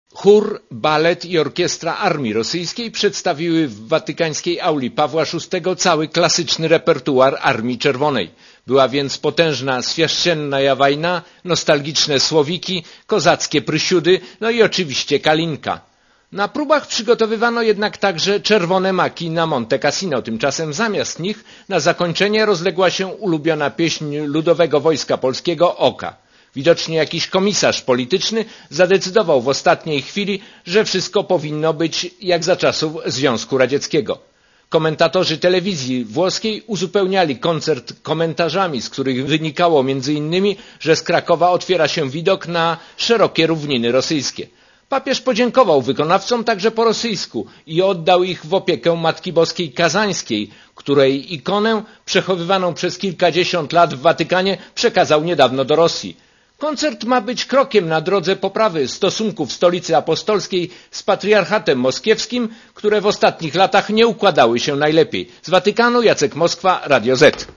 Duchową pielgrzymką papieża do Rosji nazwali komentatorzy koncert chóru im. Aleksandrowa w Auli Pawła VI z okazji 26. rocznicy pontyfikatu Jana Pawła II.
Korespondencja z Watykanu
Zorganizowany przez Ministerstwo Obrony Rosji koncert nagrodzony został przez Jana Pawła II i kilka tysięcy widzów oklaskami.